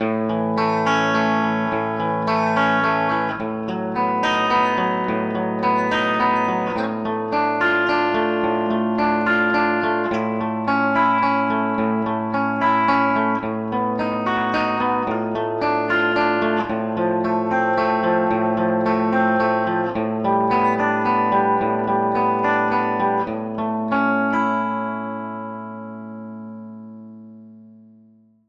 UNIQUE CONVOLUTION REVERBS
Here is a blend of four different IRs—a shower, stairwell, spring reverb, and a trash compactor—on an electric guitar recording: